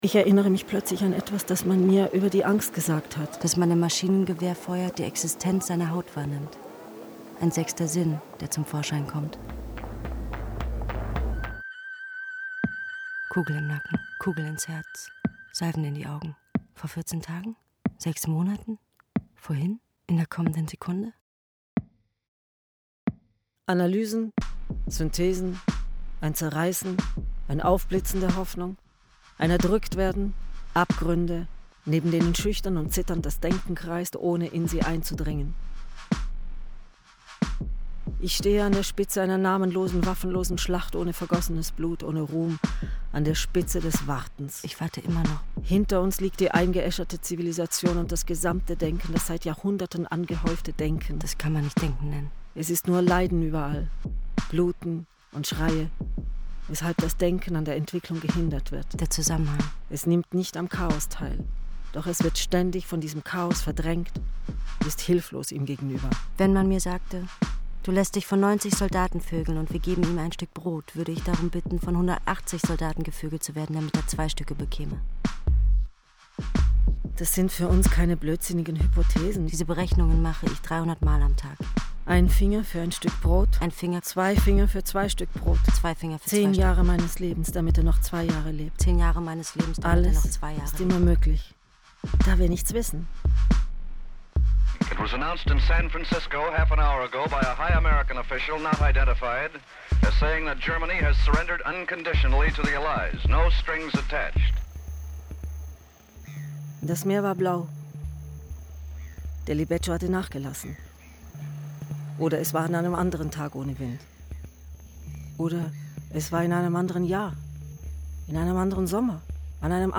Die Audio-Collage verwendet Textzitate aus “Hefte aus Kriegszeiten” (Duras) und “Das Menschengeschlecht” (Antelme).
Aufnahmen und Mischung @ Deutschlandradio Kultur